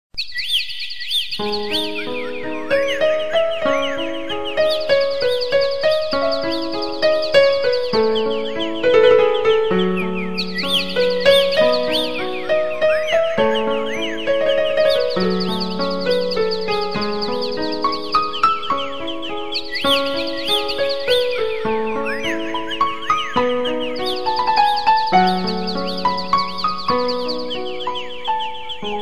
birds-twittes.mp3